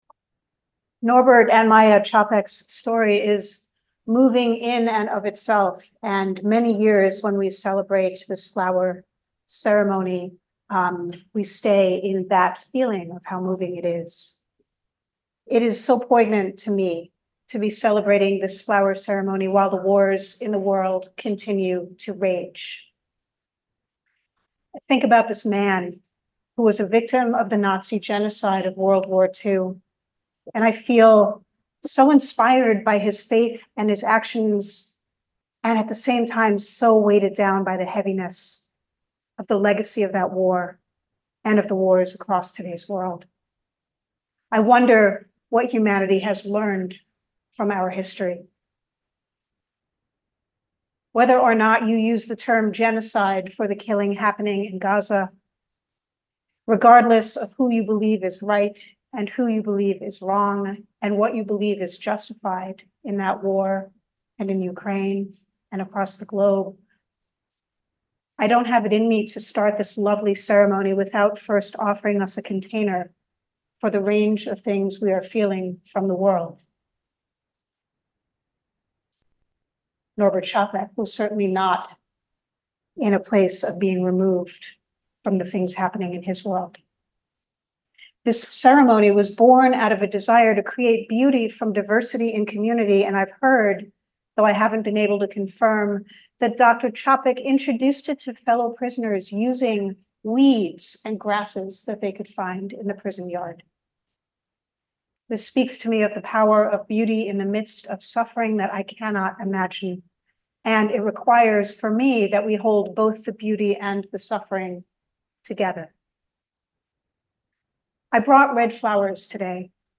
We’ll hear our choir, listen to Čapek’s story, and sing together.